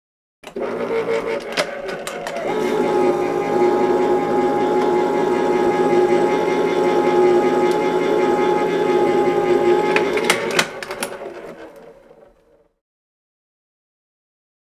Electric Can Opener Sound
household